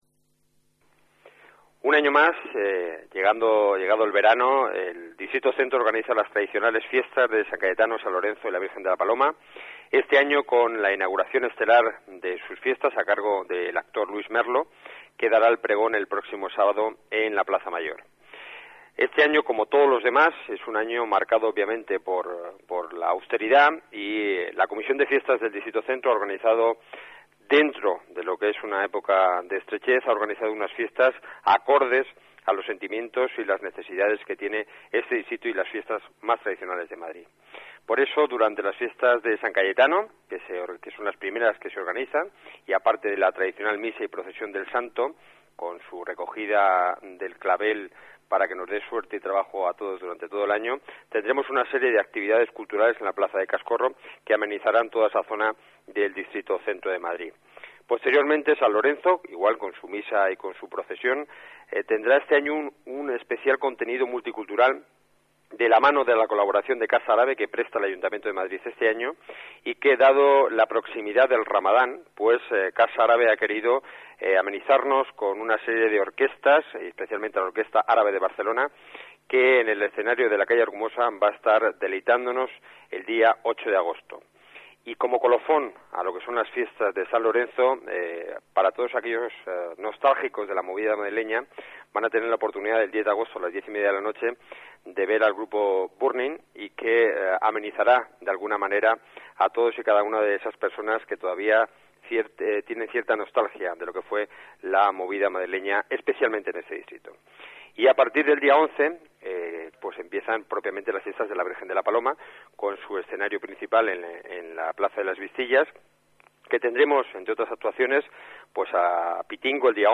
Nueva ventana:Declaraciones concejal de Centro, Enrique Núñez: Fiestas de La Paloma